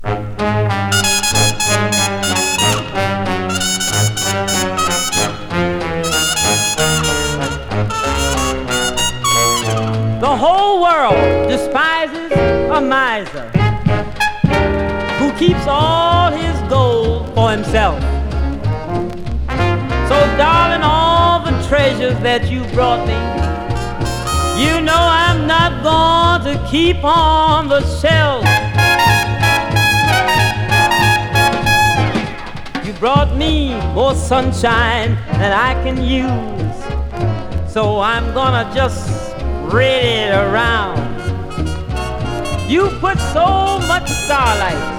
演技同様にさまざまな歌い分け、バックバンドの演奏も冴えてます。
Jazz, Pop, Vocal　USA　12inchレコード　33rpm　Mono